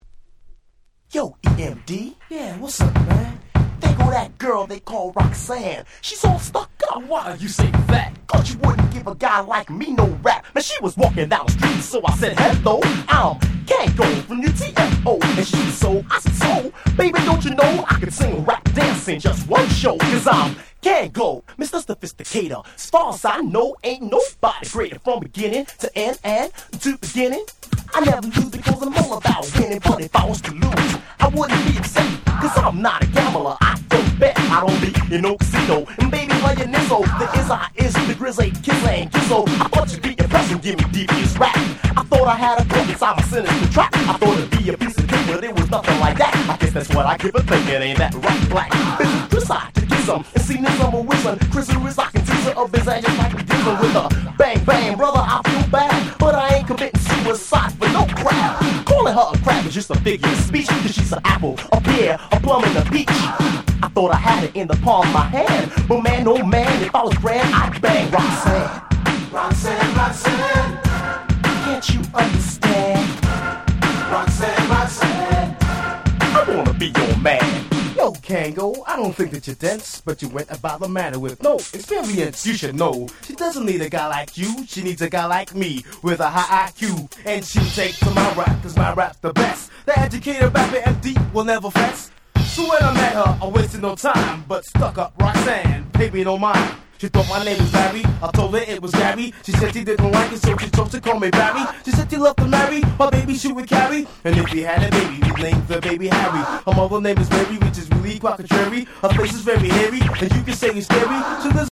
84' Super Hip Hop Classics !!